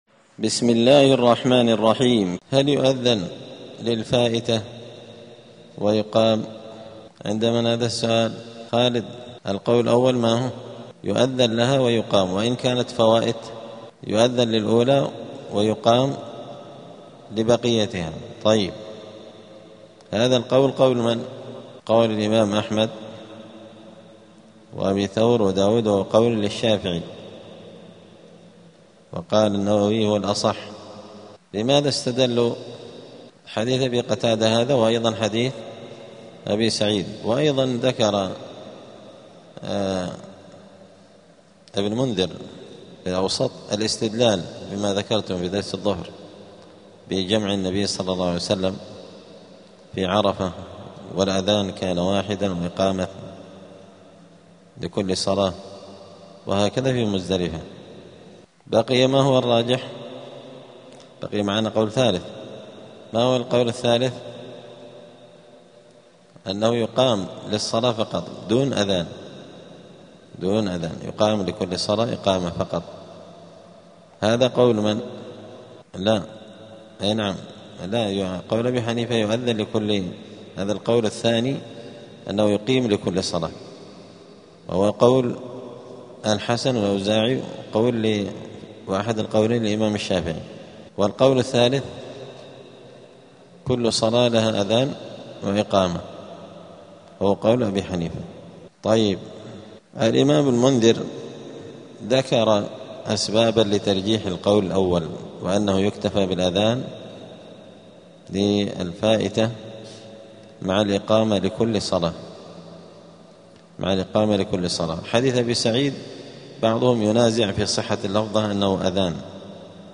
دار الحديث السلفية بمسجد الفرقان قشن المهرة اليمن
*الدرس الرابع والأربعون بعد المائة [144] {هل يؤذن للفائتة ويقام}*